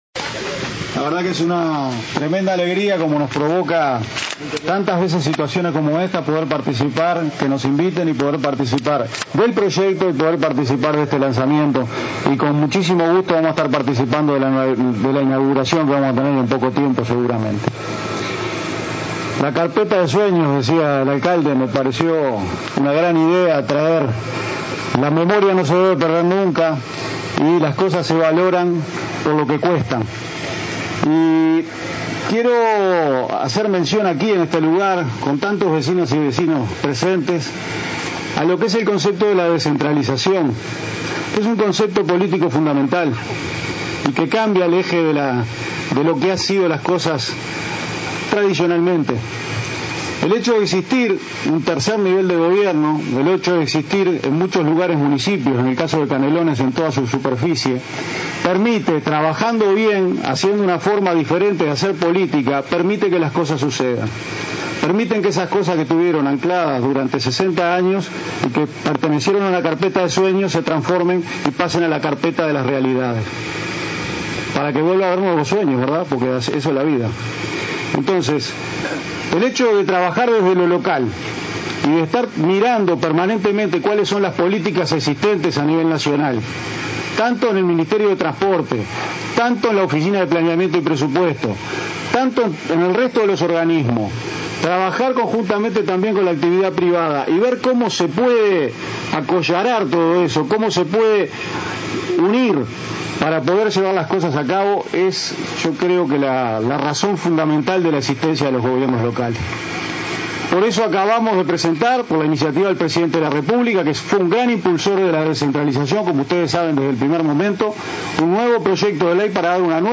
El director de OPP, Álvaro García, insistió durante el lanzamiento de las obras del Parque Metropolitano de La Paz, Canelones, en el concepto de descentralización.